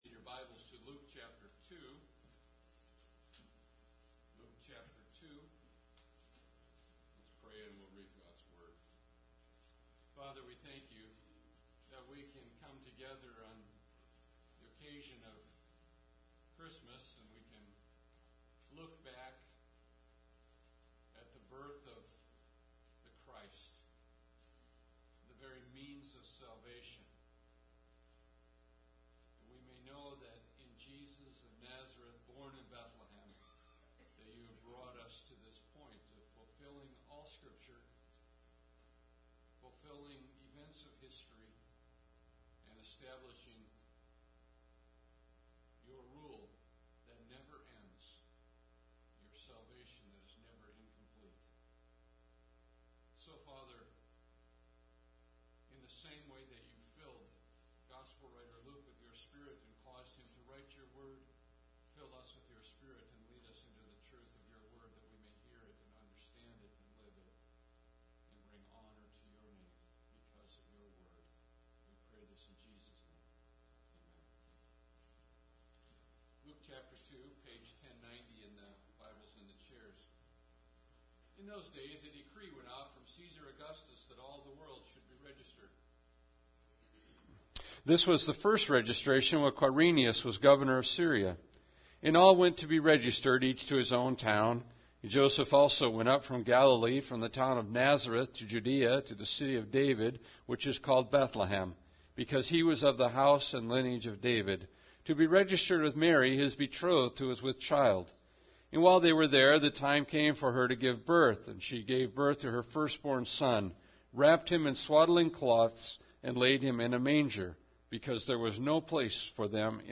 Bible Text: Luke 2:1-20 (Poor Sound Quality – Turn volume to max)
Service Type: Sunday Service